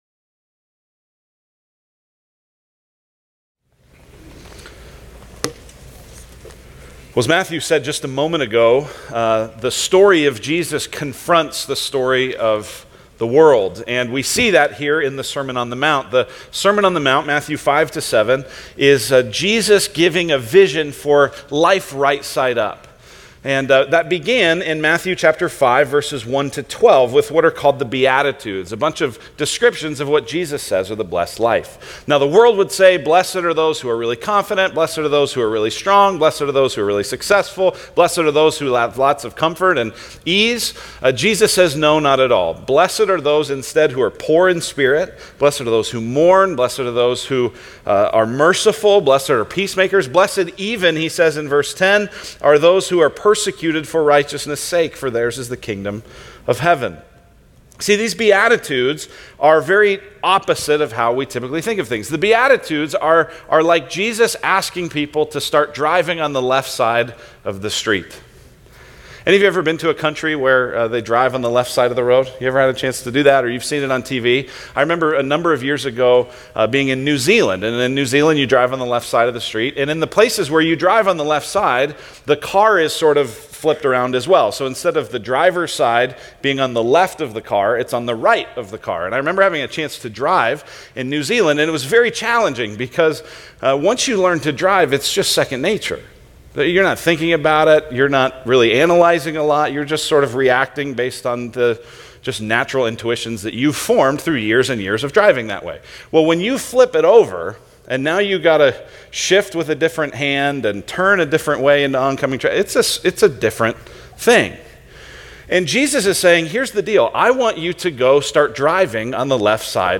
The Sermon on The Mount: The Demanding Word of God